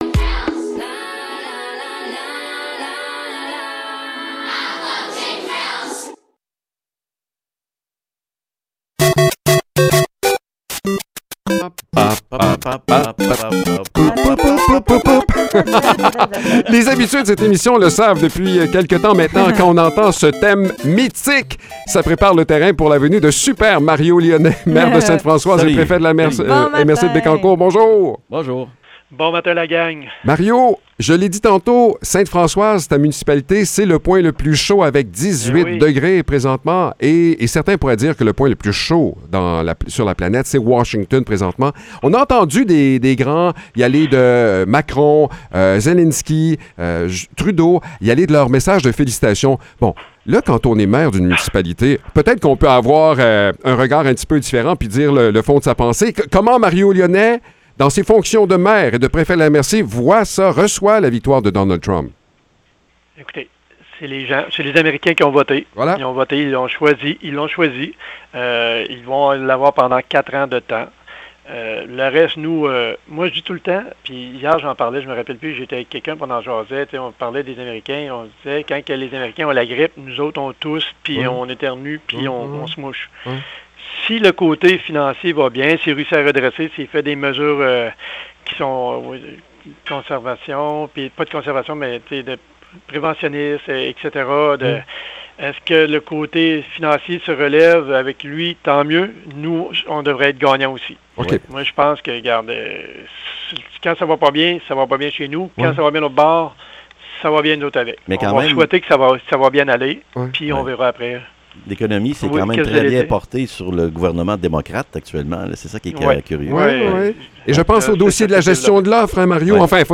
Échange avec Mario Lyonnais
Mario Lyonnais, maire de Ste-Françoise et préfet de la MRC de Bécancour, réagit aux élections américaines et nous parle de ce qui est au menu du conseil de ce soir.